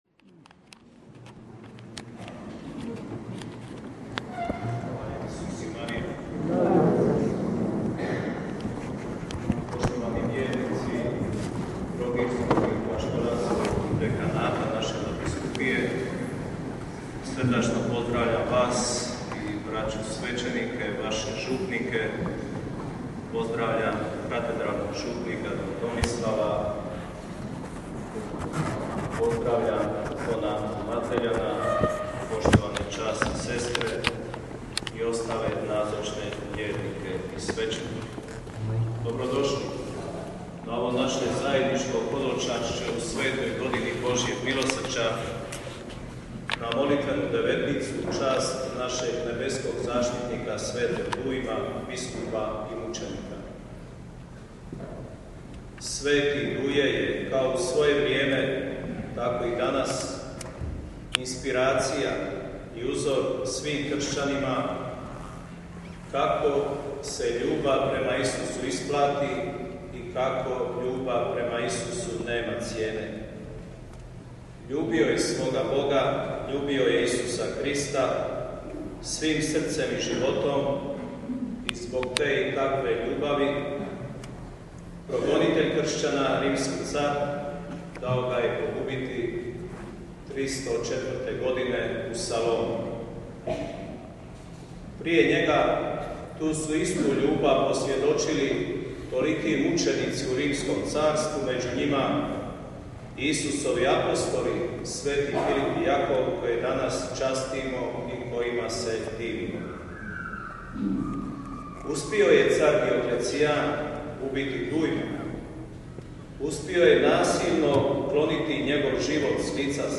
PROPOVIJED
Šestog dana devetnice sv. Dujmu, u utorak 3. svibnja, u splitsku katedralu hodočastili su vjernici župa Trogirskog i Kaštelanskog dekanata sa svojim svećenicima.
Na misi je pjevao Mješoviti zbor župe Uznesenja Blažene Djevice Marije iz Kaštel Lukšića.